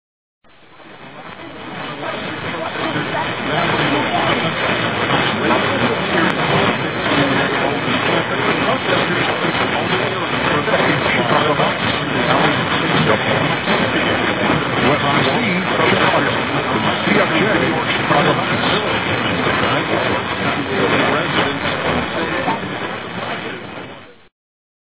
This page contains DX Clips from the 2011 DX season!
WSBC Chicago, IL 1240 at 11PM. 10/26/11 Announcer states sister stations as well on brief fade in on the hour.